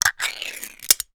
Can Fruit Cup Open Sound
household